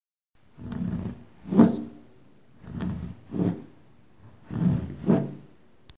Serrando un objeto
Grabación sonora del sonido que produce una sierra de mano al cortar un objeto macizo de madera o cartón.
Sonidos: Acciones humanas